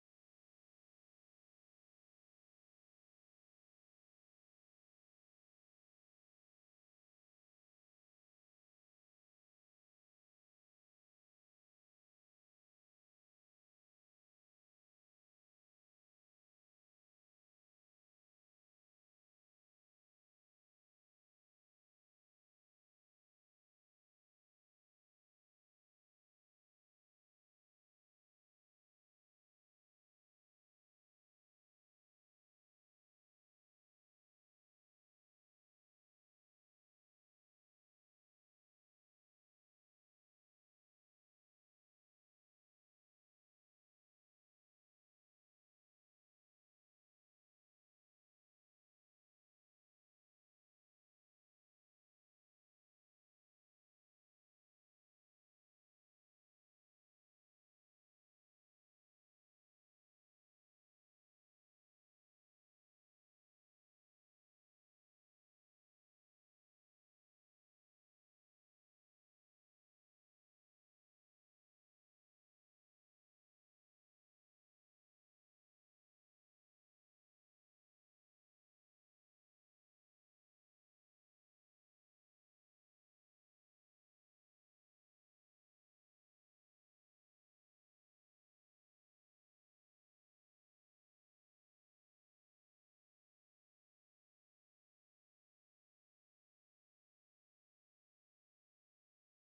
ライブ・アット・シャトー・ネウフ、オスロ、ノルウェー、11/09/1971
※試聴用に実際より音質を落としています。